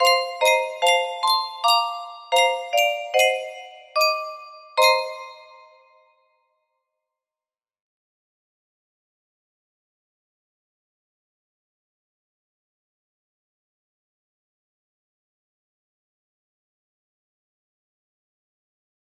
Maiyu tune music box melody